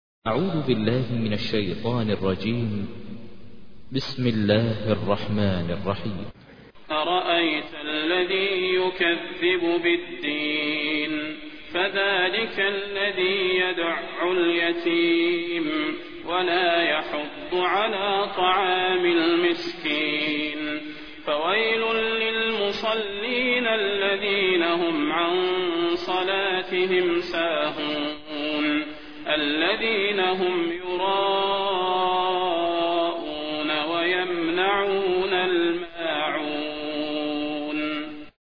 تحميل : 107. سورة الماعون / القارئ ماهر المعيقلي / القرآن الكريم / موقع يا حسين